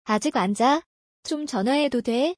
アジアン ジャ？ チョ チョナヘド ドェ？